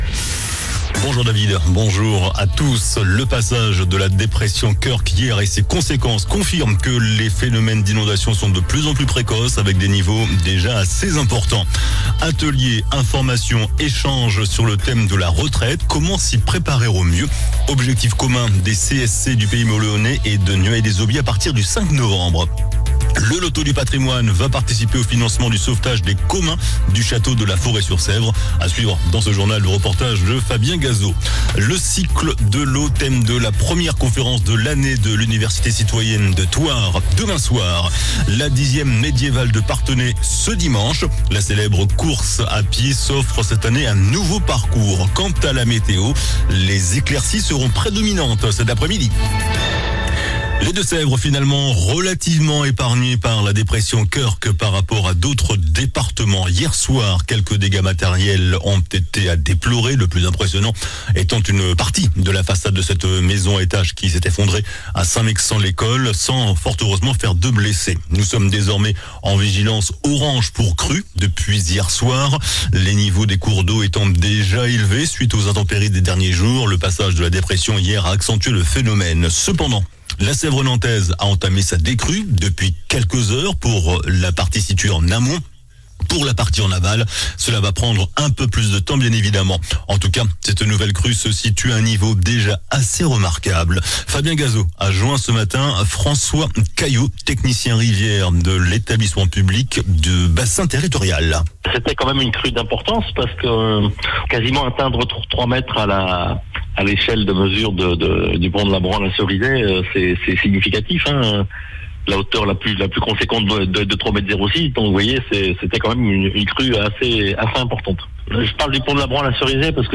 JOURNAL DU JEUDI 10 OCTOBRE ( MIDI )